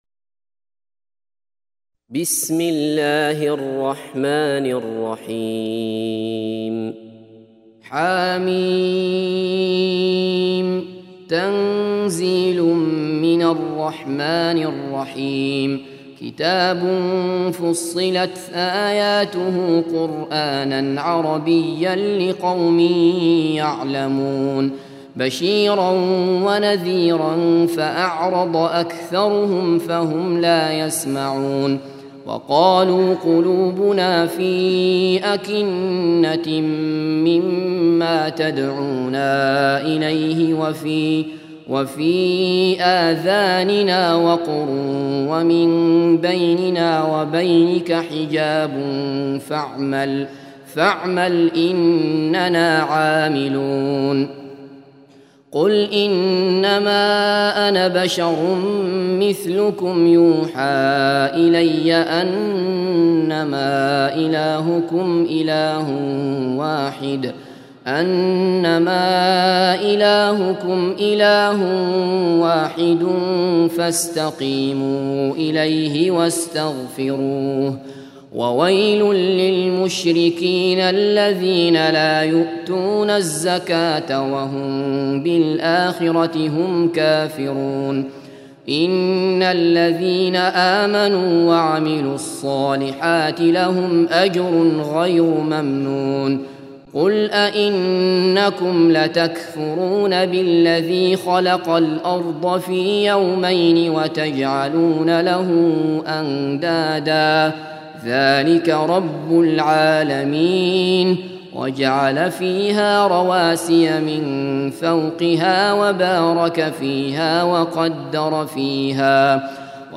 41. Surah Fussilat سورة فصّلت Audio Quran Tarteel Recitation
Surah Sequence تتابع السورة Download Surah حمّل السورة Reciting Murattalah Audio for 41. Surah Fussilat سورة فصّلت N.B *Surah Includes Al-Basmalah Reciters Sequents تتابع التلاوات Reciters Repeats تكرار التلاوات